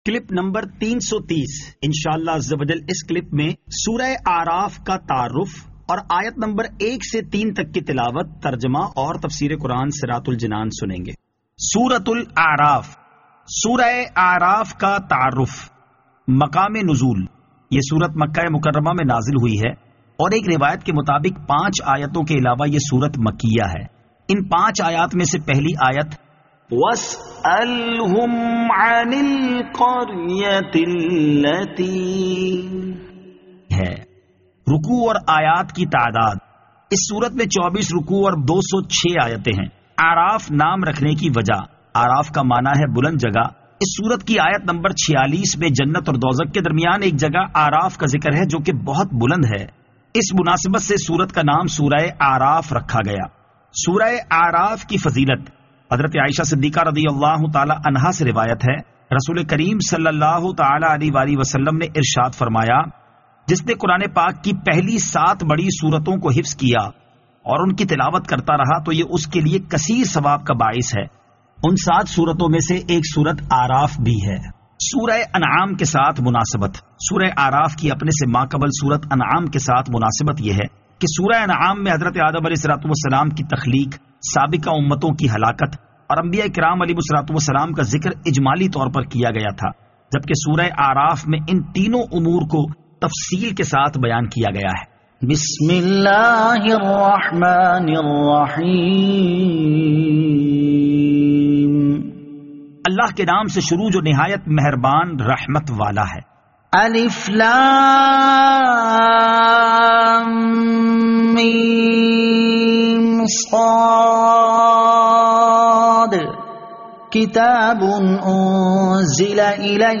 Surah Al-A'raf Ayat 01 To 03 Tilawat , Tarjama , Tafseer